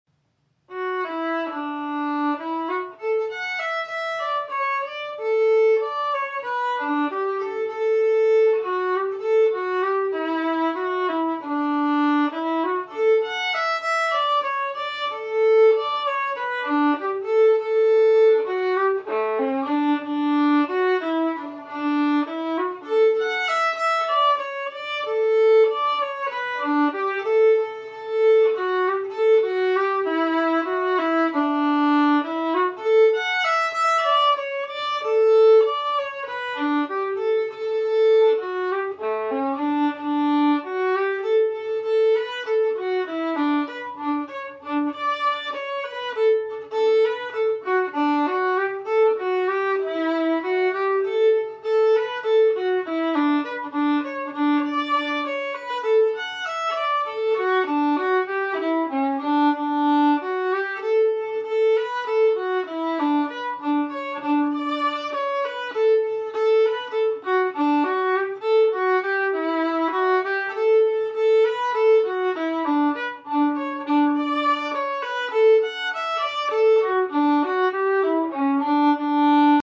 Key: G
Form: Slow Air
fiddle